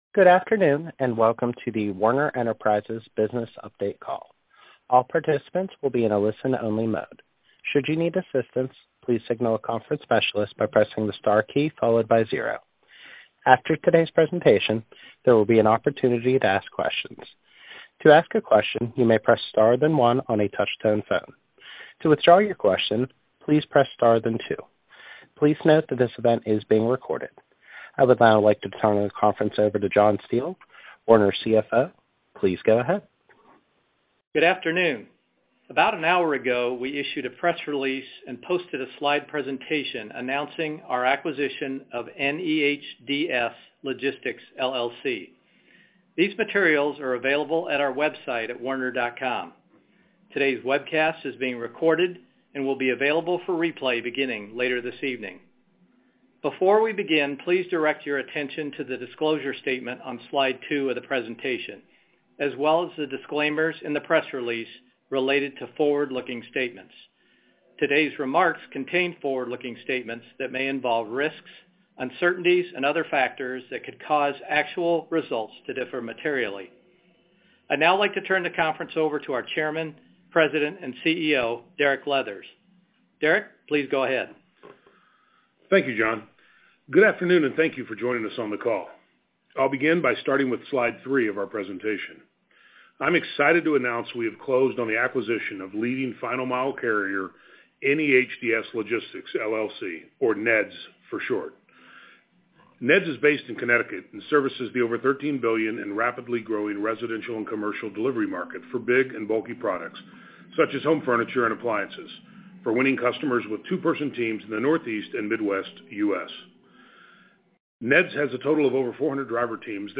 NEHDS-Acquisition-Conference-Call.mp3